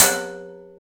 PRC BOWL H0I.wav